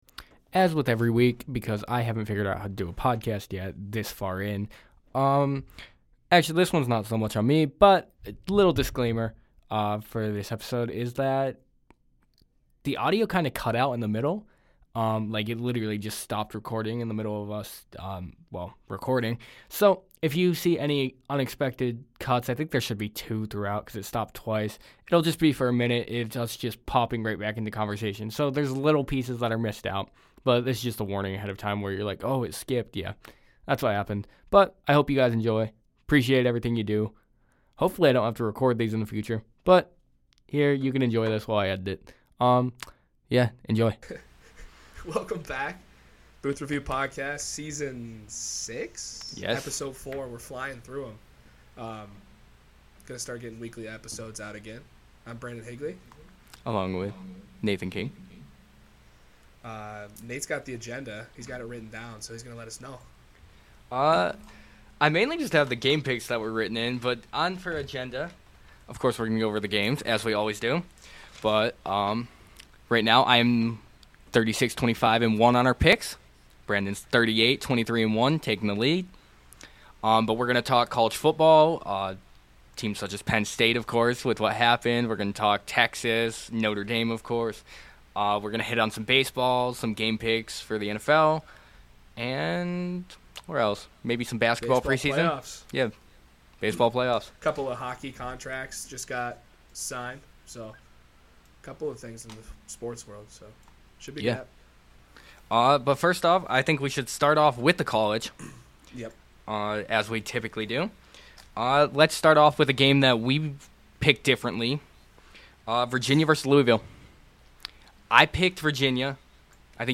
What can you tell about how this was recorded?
Live every Thursday at 6:30 PM.